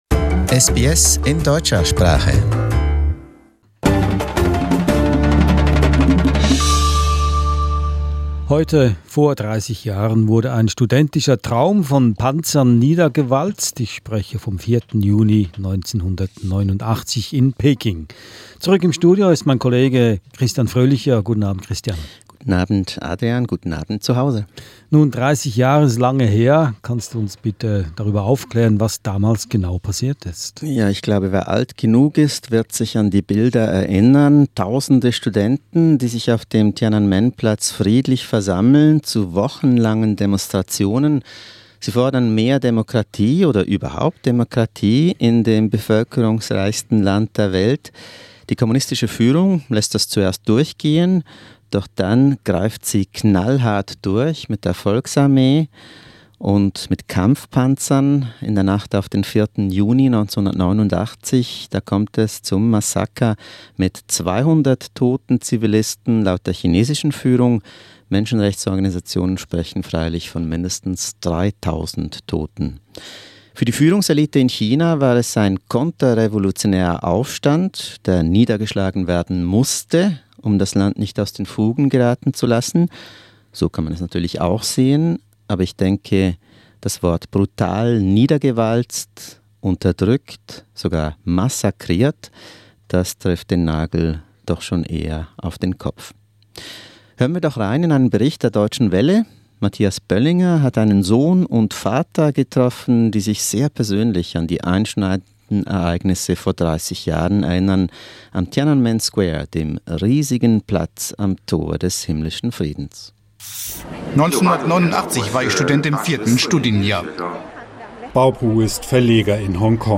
An SBS studio talk to mark the 4th of June 1989.